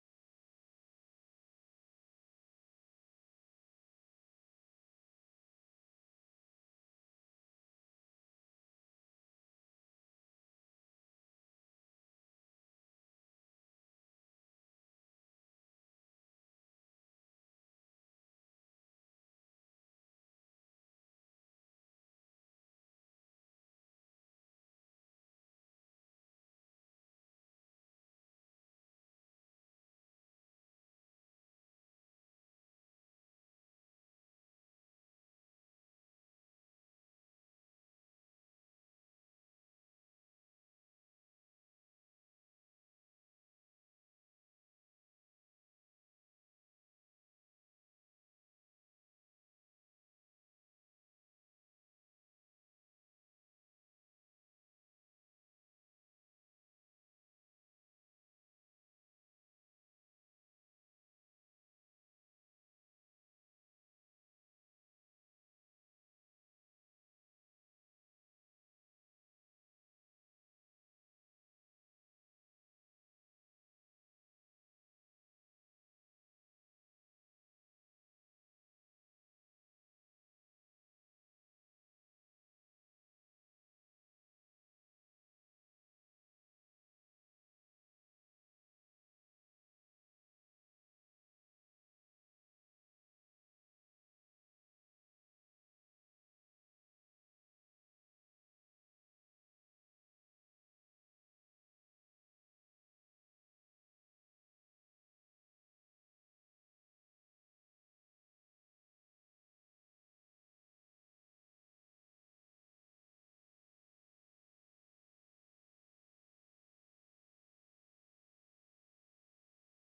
testsilence.ogg